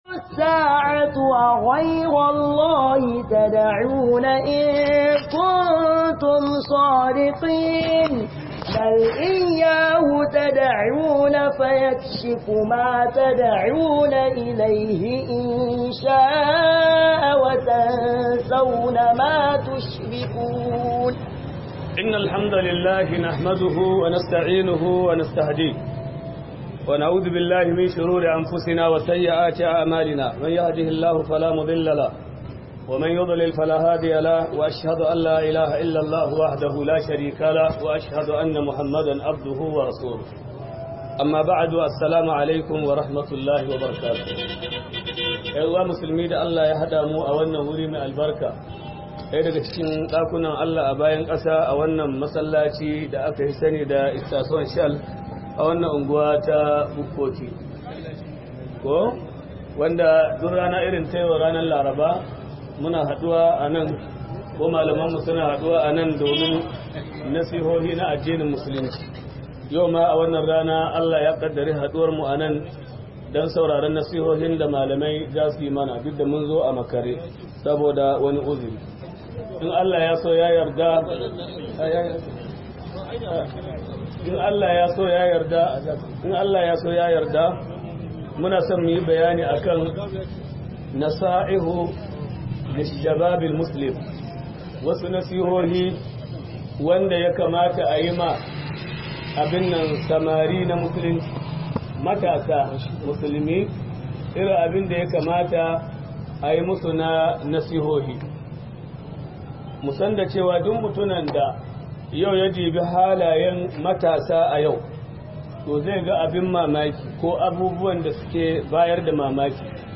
نصائح الشباب المسلم - MUHADARA